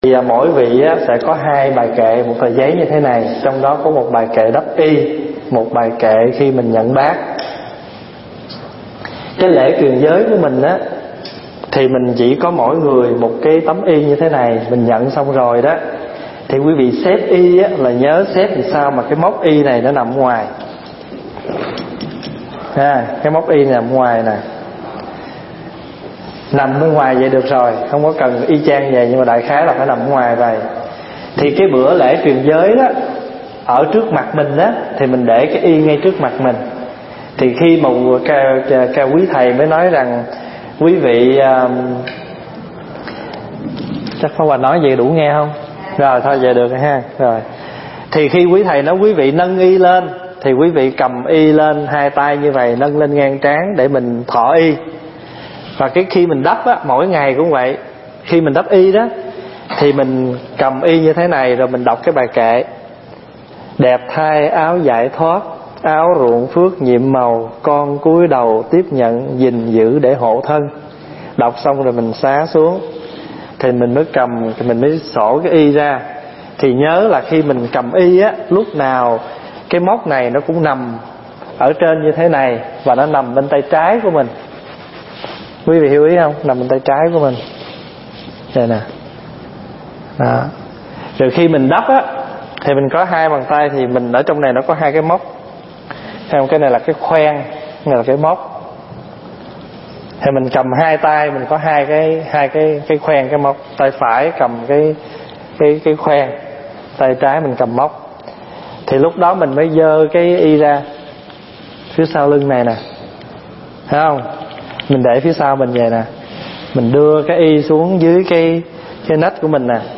Nghe Mp3 thuyết pháp Nghi Thức Đắp Y và Nhận Bát (Hướng Dẫn)
tại Tu Viện Trúc Lâm, Canada